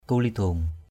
/ku-li-d̪o:ŋ/ (d.) đại dương = océan. di krâh kulidong d} k;H k~l{_dU giữa đại dương.